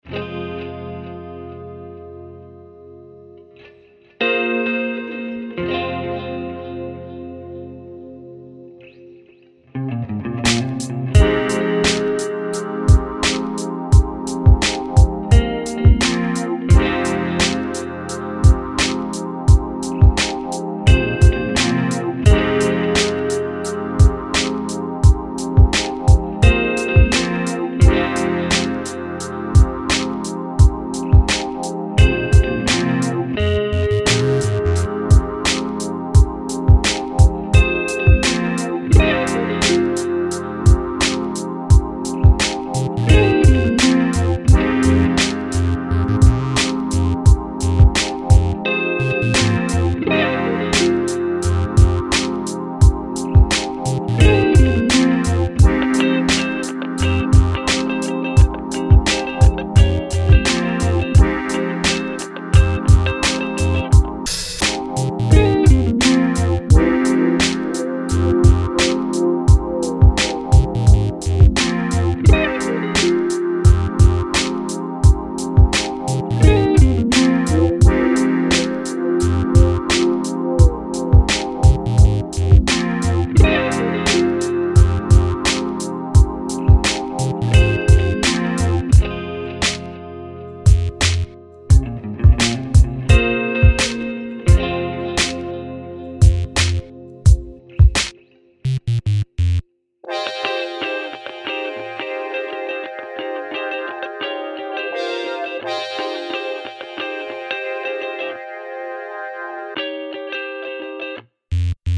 I'm hearing a bit of high frequency fuzz and occasionally slight clipping but I'm not sure if it's the recording or the speakers.